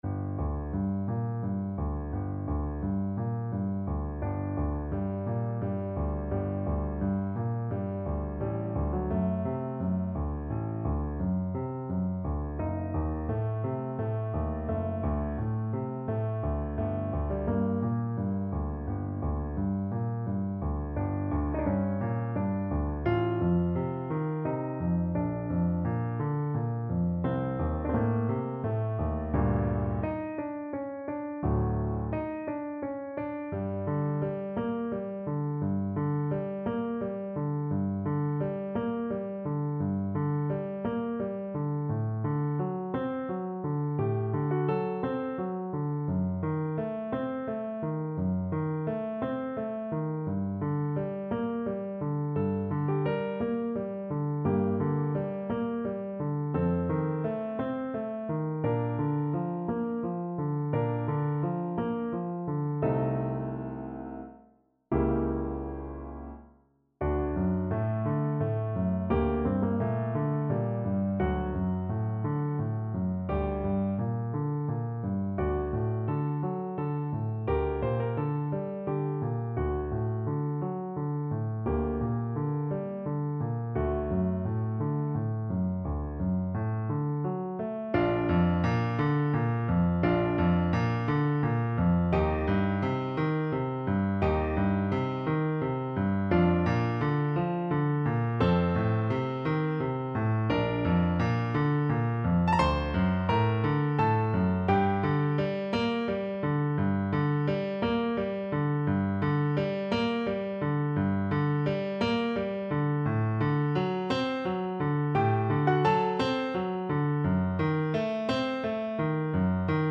Play (or use space bar on your keyboard) Pause Music Playalong - Piano Accompaniment Playalong Band Accompaniment not yet available reset tempo print settings full screen
6/8 (View more 6/8 Music)
G minor (Sounding Pitch) (View more G minor Music for Recorder )
Larghetto = c. 86
Classical (View more Classical Recorder Music)